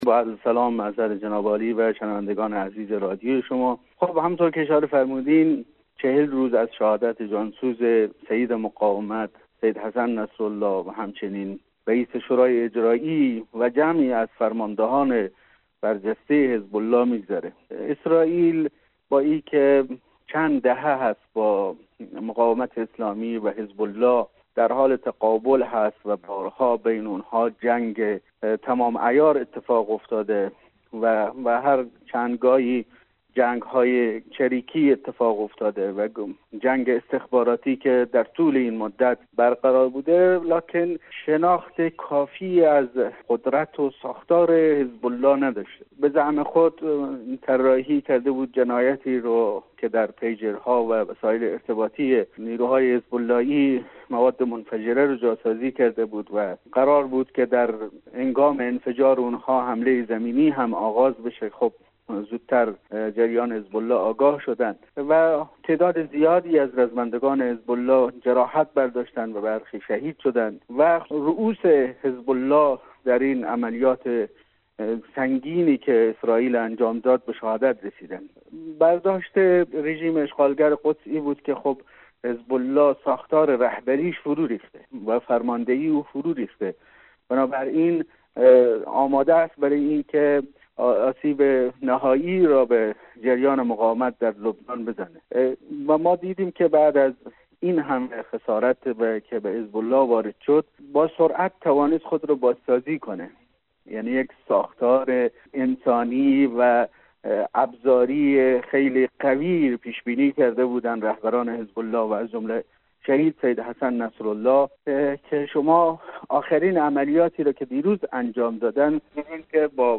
در گفت و گو با برنامه انعکاس رادیو دری